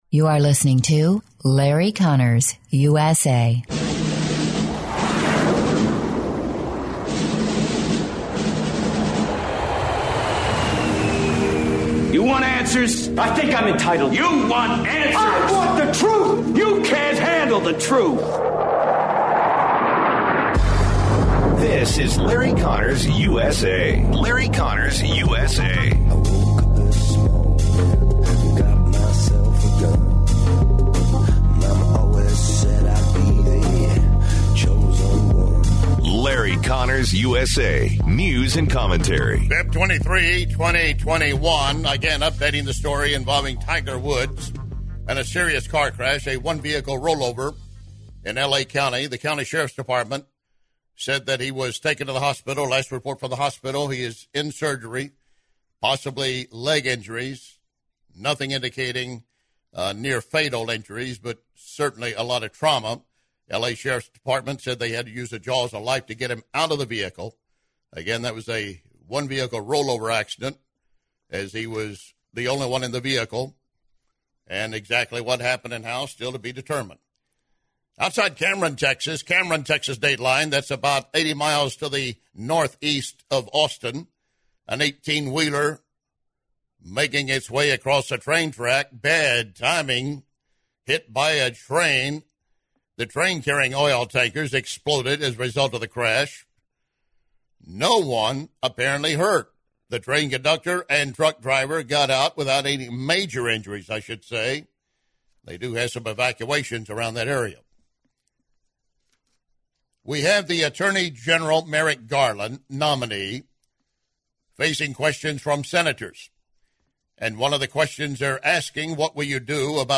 news and commentary. He focuses on Senator Hawley and AG nominee Garland’s question and response towards border security.